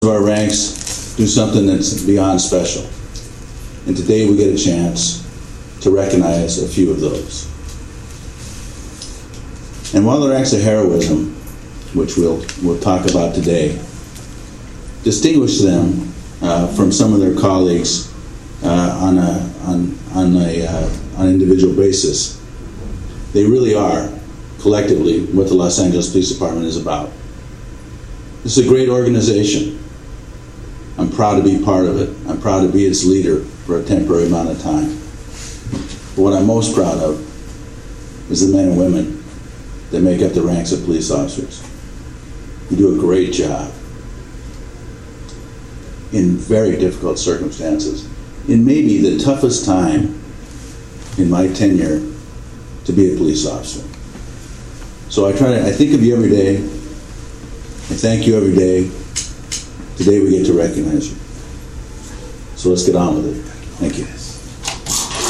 The ceremony was held in the Ronald Deaton Auditorium.